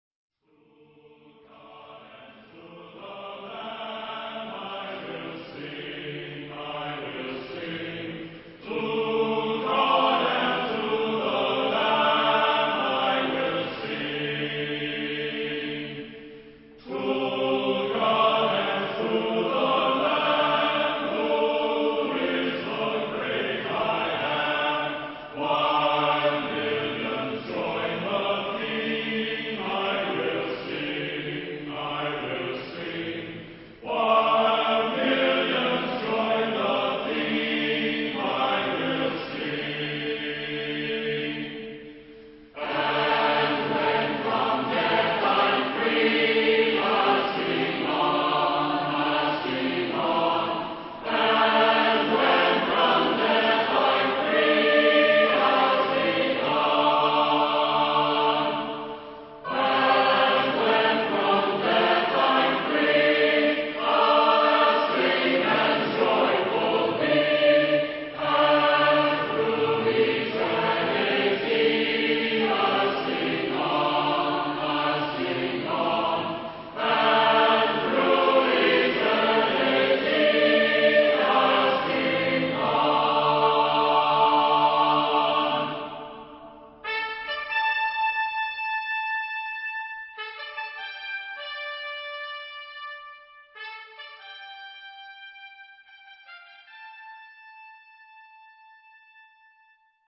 Genre-Style-Forme : Sacré ; contemporain ; Hymne (sacré)
Caractère de la pièce : majestueux
Solistes : 1 au choix  (1 soliste(s))